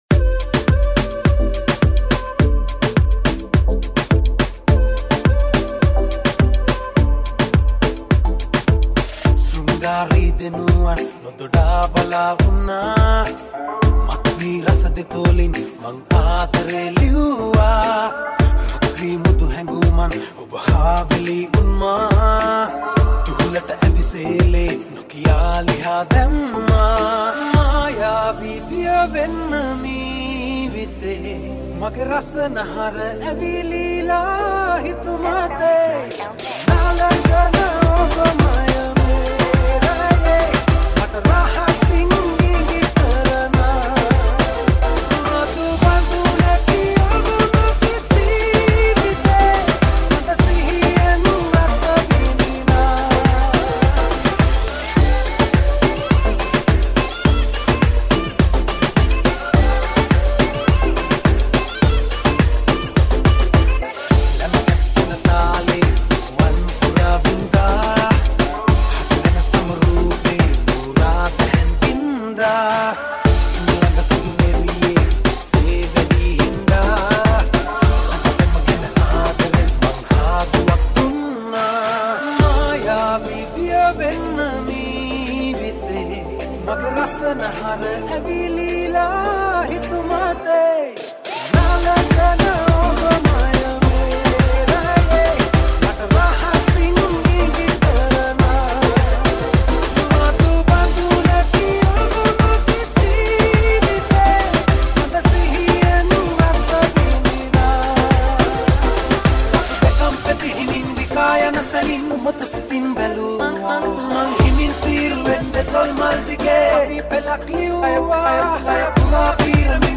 High quality Sri Lankan remix MP3 (2.8).